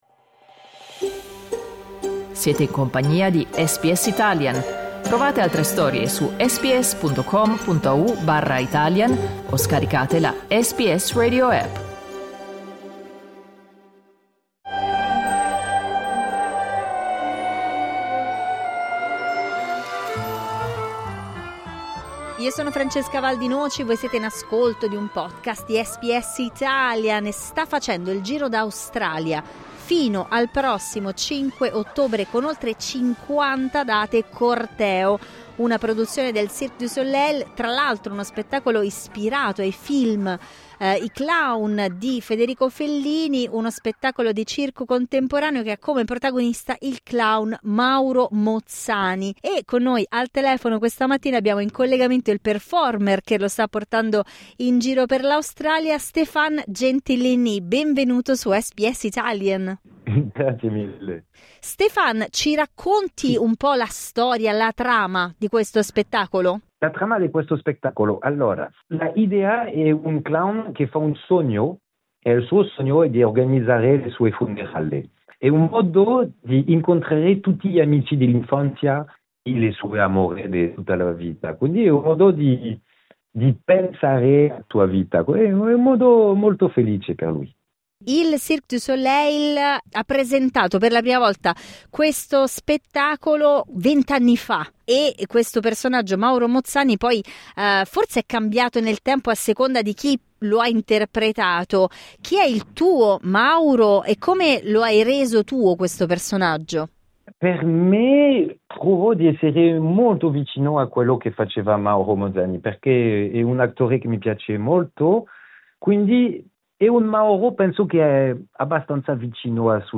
Clicca sul tasto "play" in alto per ascoltare l'intervista Corteo